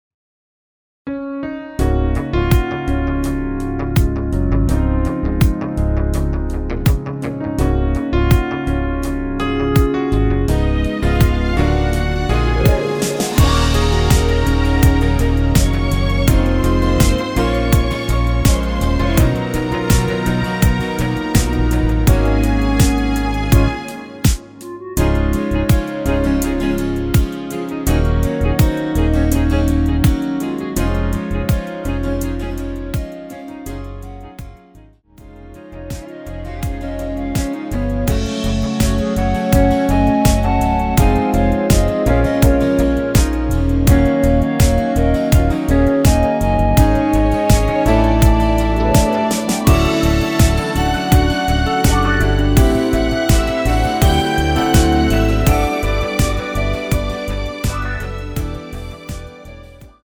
원키에서(-2)내린 멜로디 포함된 MR입니다.
Db
멜로디 MR이라고 합니다.
앞부분30초, 뒷부분30초씩 편집해서 올려 드리고 있습니다.
중간에 음이 끈어지고 다시 나오는 이유는